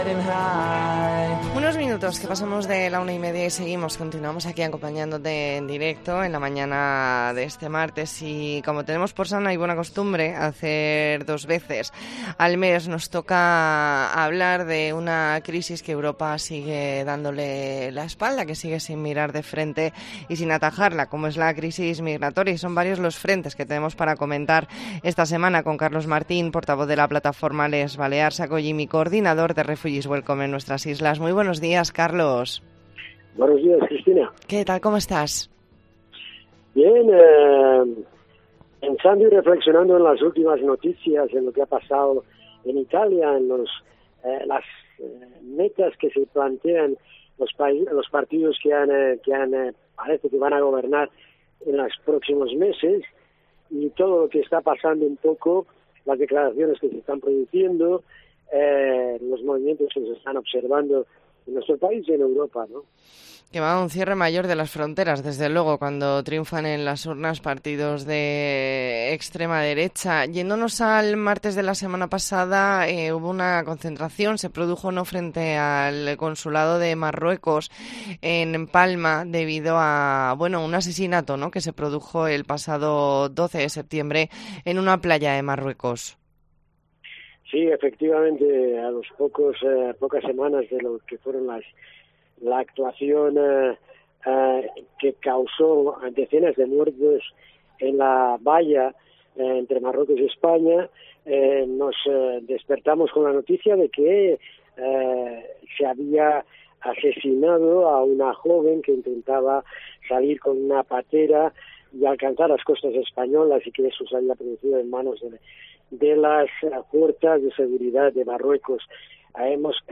E ntrevista en La Mañana en COPE Más Mallorca, martes 27 de septiembre de 2022.